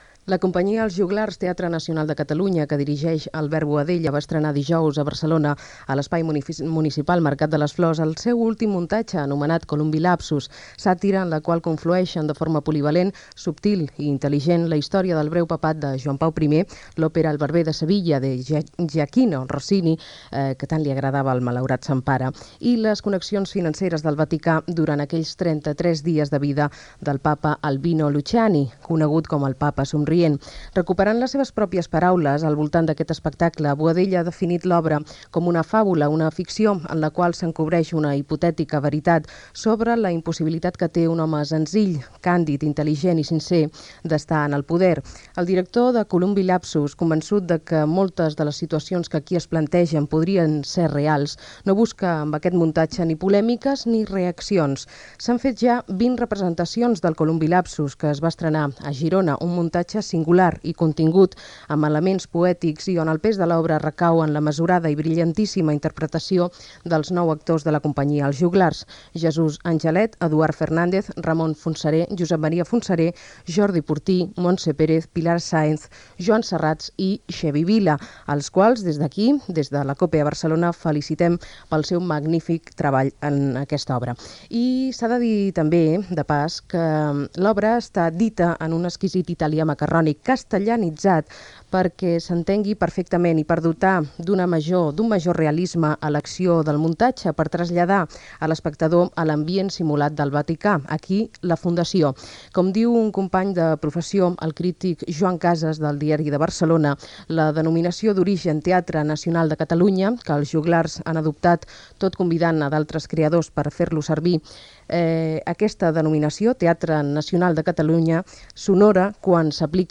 Els Joglars representen al Mercat de les Flors de Barcelona l'obra "Columbi lapsus", entrevista al director de teatre Albert Boadella